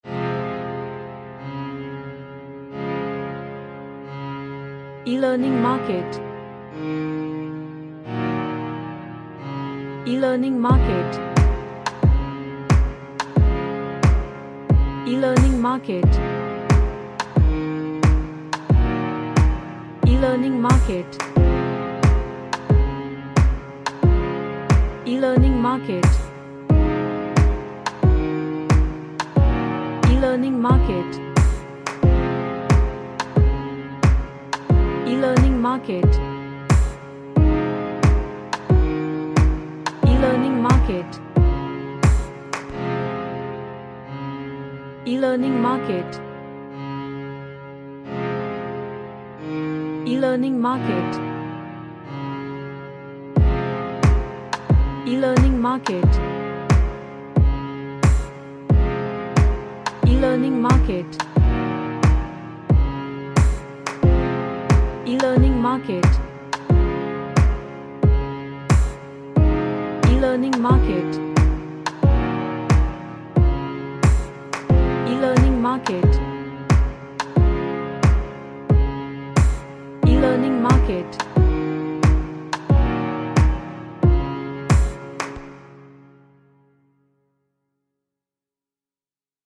A piano instrumental with heavy Chords.
Relaxation / Meditation